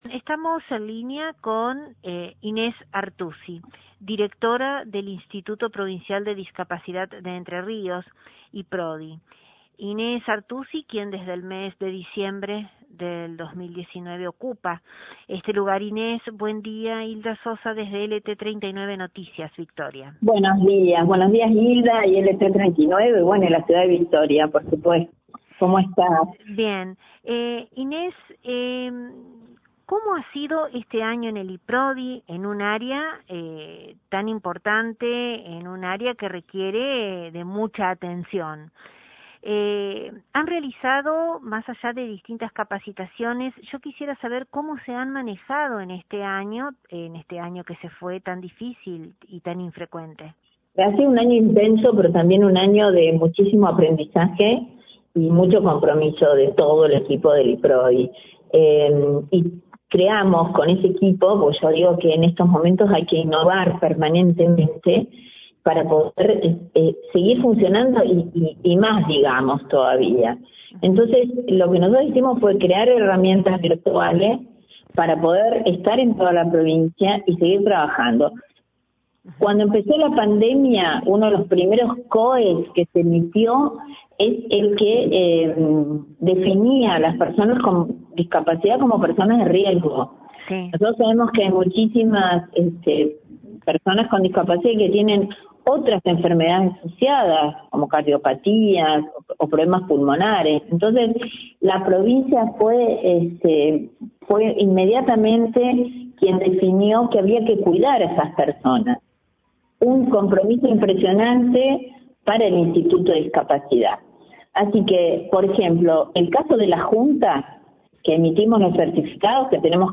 Desde LT39 NOTICIAS, dialogamos con su Directora Inés Artussi, sobre este importante organismo gubernamental, tan caro a una población determinada de nuestra sociedad; quien prima facie, destacó la prórroga de los CUD, certificados únicos de discapacidad, que extiende la provincia.